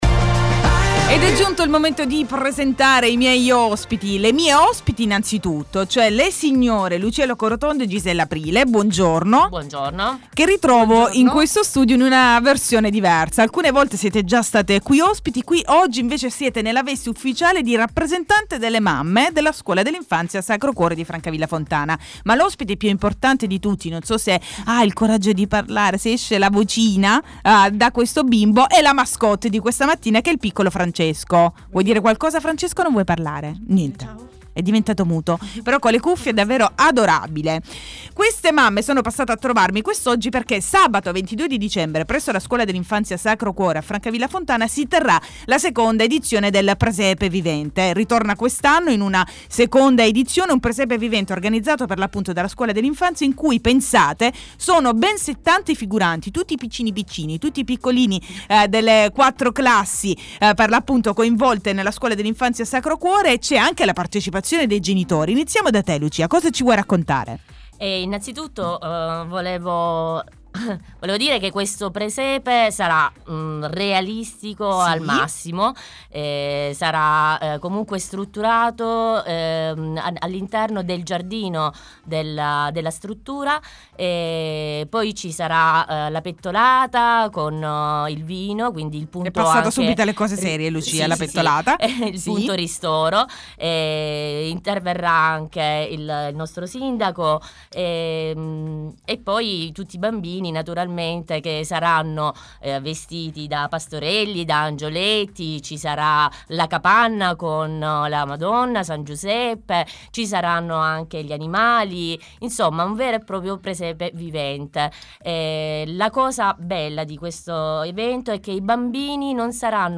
Il Mattino di Radio85 - Intervista sul presepe vivente ideato dalla scuola dell'infanzia Sacro Cuore di Francavilla Fontana